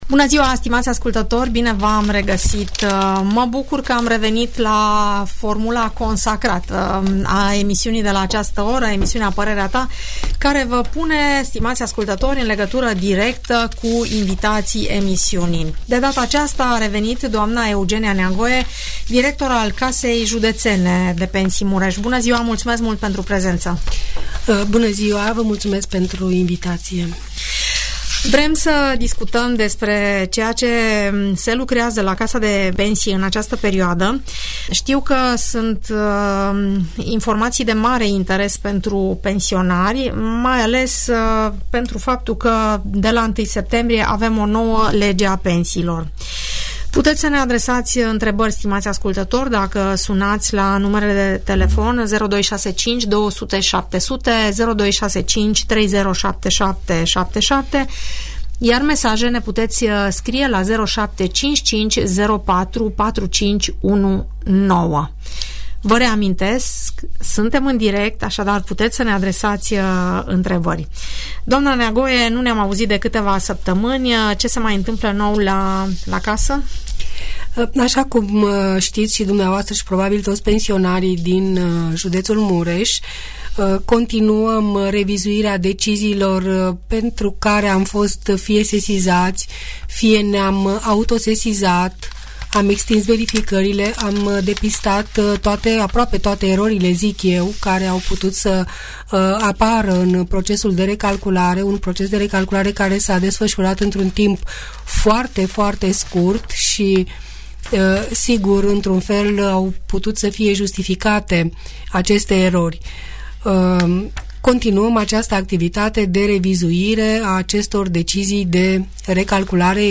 După 2 luni de la intrarea în vigoare a Legii 360, privind recalcularea pensiilor, se pot trage câteva concluzii despre efectele acestui act legislativ. În emisiunea "Părerea ta", mulți pensionari cer explicații deoarece unele aspecte sunt încă neclare.